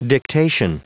Prononciation du mot dictation en anglais (fichier audio)
Prononciation du mot : dictation